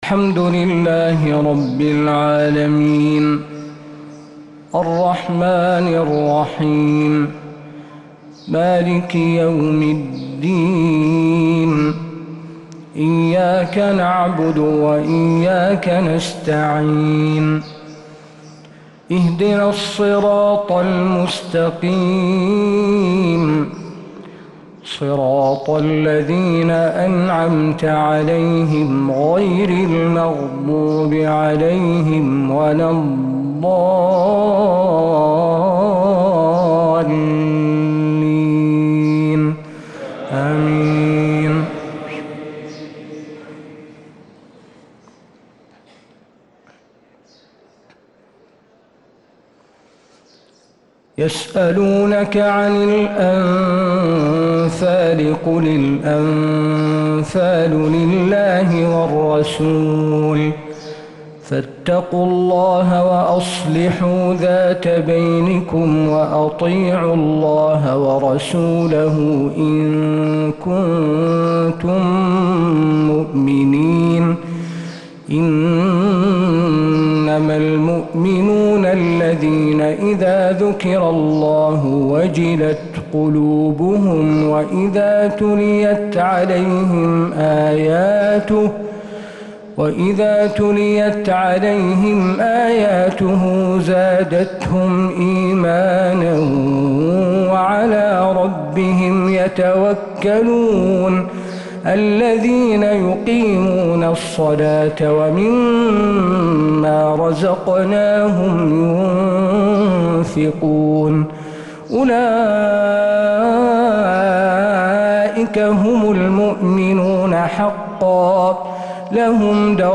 فجر الخميس 7-8-1446هـ فواتح سورة الأنفال 1-23 | Fajr prayer from Surat al-Anfal 6-2-2025 > 1446 🕌 > الفروض - تلاوات الحرمين